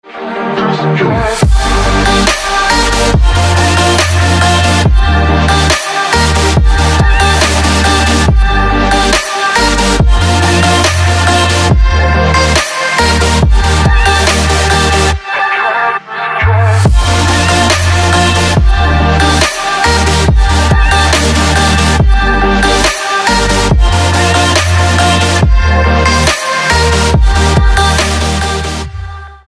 • Качество: 256, Stereo
Electronic
EDM
Trap
club